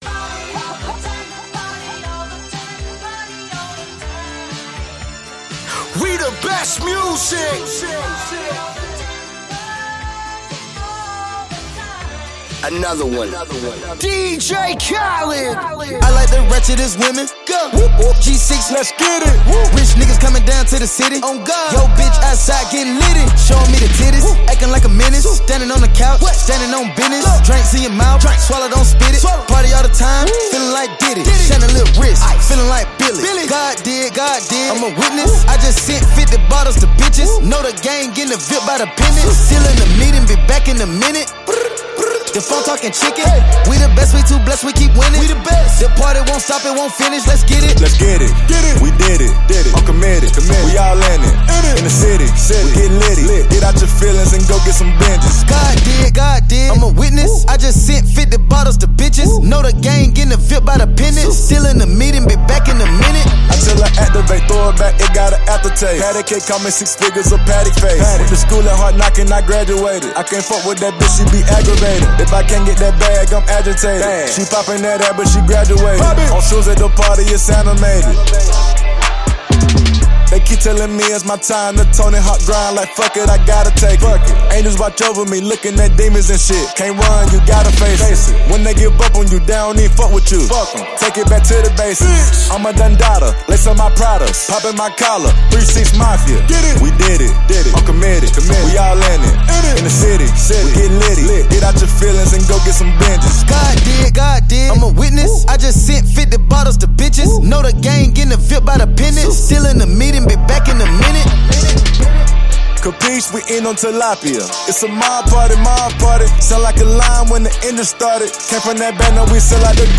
Each song has a strong beat and powerful lyrics.